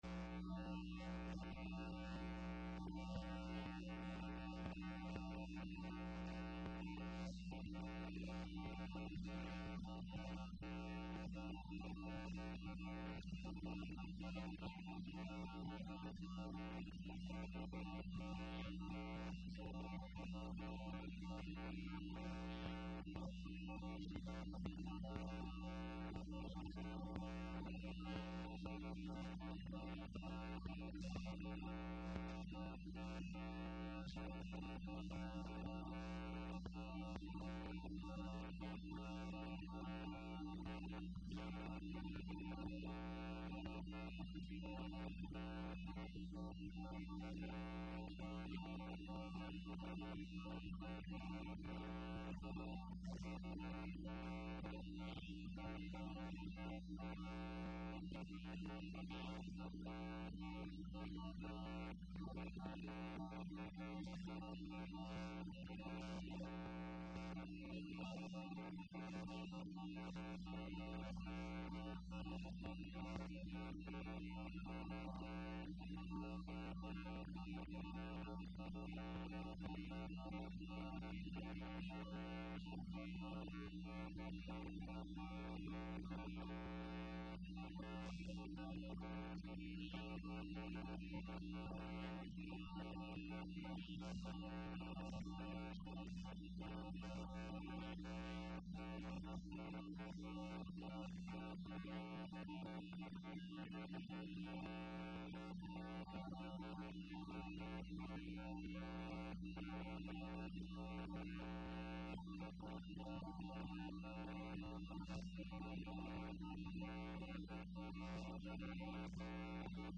testified before the House Committee on Financial Services at an April 14, 2010 hearing to discuss reforming the regulatory framework for housing finance.